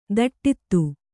♪ daṭṭittu